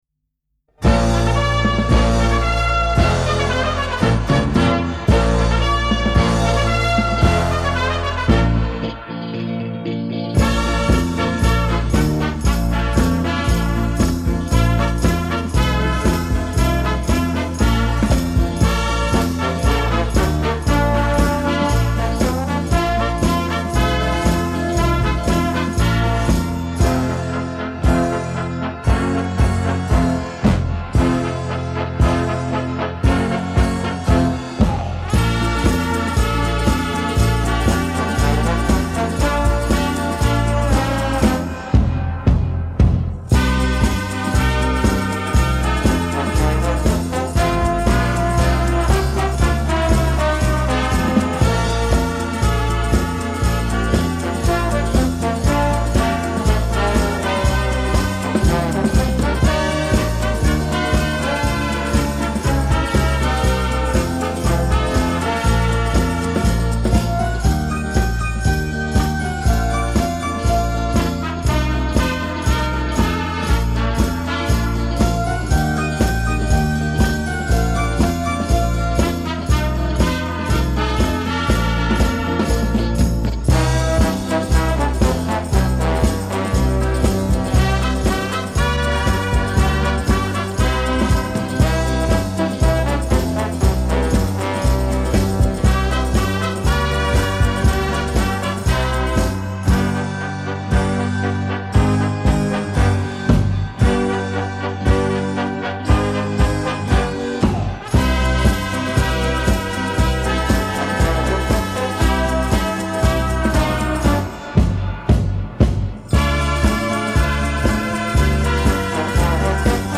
格式：卡带转 WAV分轨
吹奏和打击合并成富有晚会欢快气氛的效果